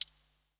53 C HH 1 -R.wav